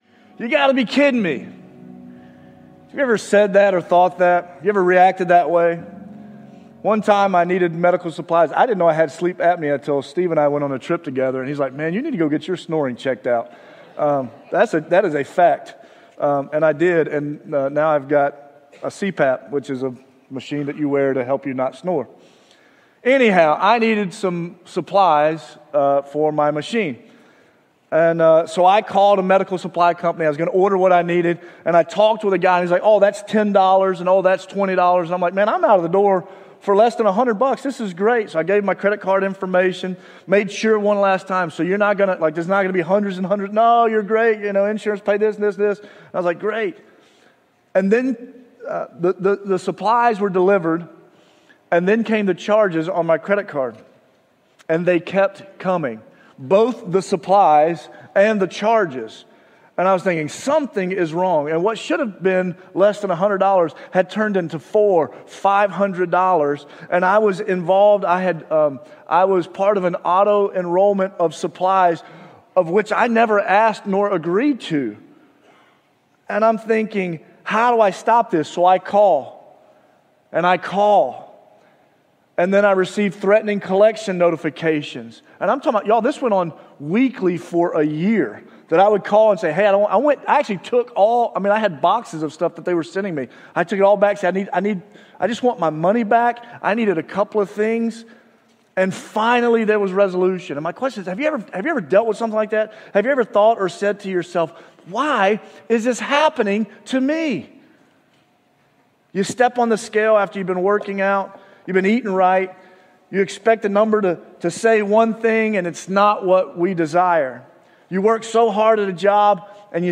Sermons | Jefferson Christian Church